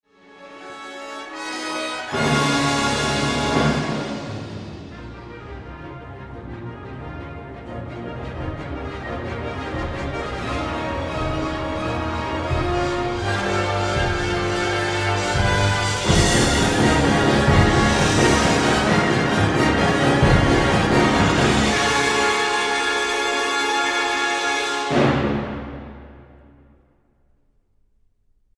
Rozpoczęcie trzeciej części jest powolne, mroczne, z długo wybrzmiewającymi uderzeniami talerzy.
Ostatnia kulminacja robi dużo wrażenie impetem i energią:
Valery Gergiev, London Symphony Orchestra, 2009, 25:30, LSO Live